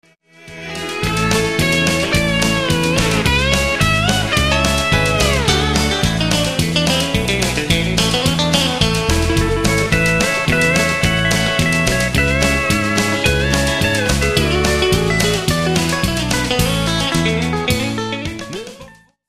Hier im semiprof - Studio entstehen z.B. Demo-Produktionen, Rundfunkspots, Jingles, Halbplaybacks, Theatermusik... - einige Beispiel-Ausschnitte gibt's als MP3_files
Neben 'richtigen' Instrumenten gibt's diverse MIDI-Klangerzeuger, NEUMANN TLM 170 Micros, TLA Compressor, YAMAHA O2R-Mixer u.s.w.
Bsp Chorus Kleinmachnow